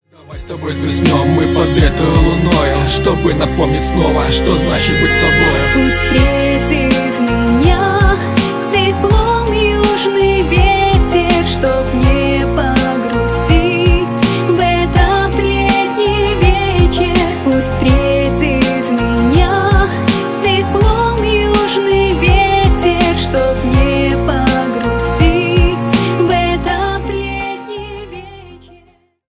Поп Музыка
грустные # тихие